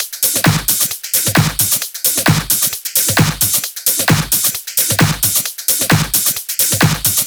VFH3 132BPM Elemental Kit 3.wav